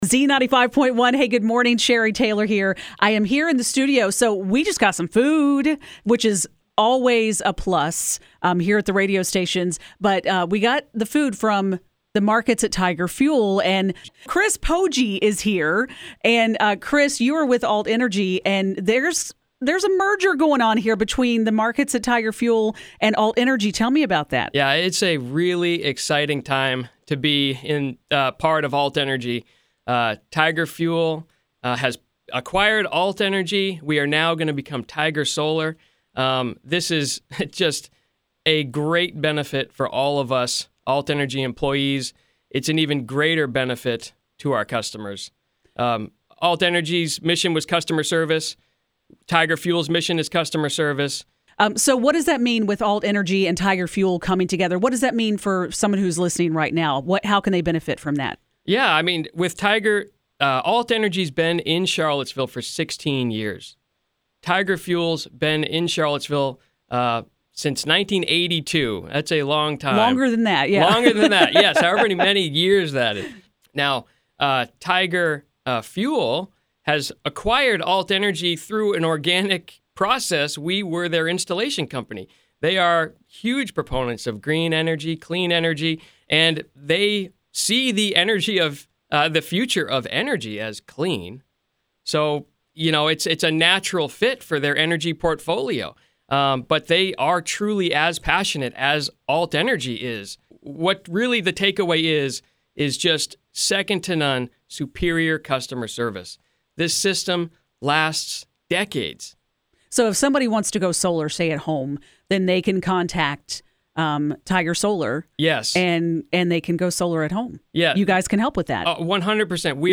Tiger-Solar-Full-Interview.mp3